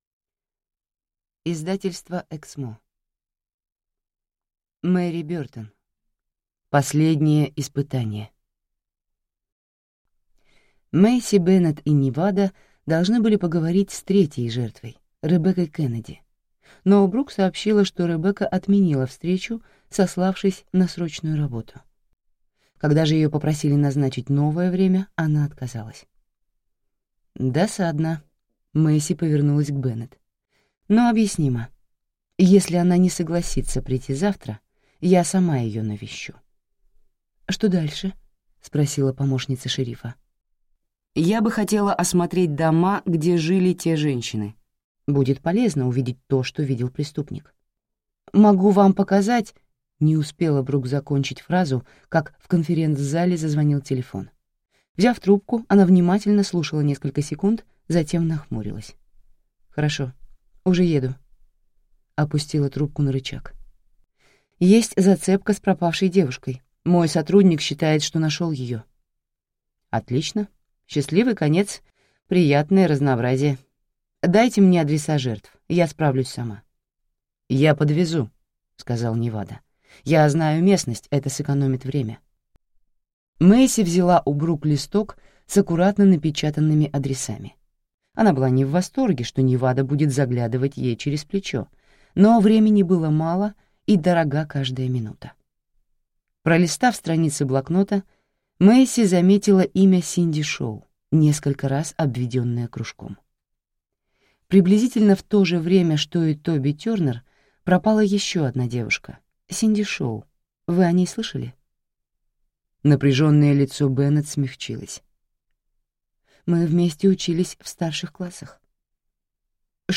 Аудиокнига Последнее испытание | Библиотека аудиокниг